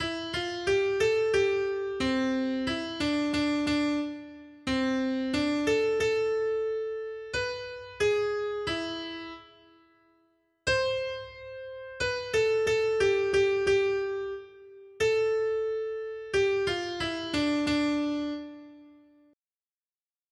Noty Štítky, zpěvníky ol180.pdf responsoriální žalm Žaltář (Olejník) 180 Ž 104, 1 Ž 104, 24 Ž 104, 29-31 Ž 104, 34 Skrýt akordy R: Sešli svého ducha, Hospodine, a obnovíš tvář země. 1.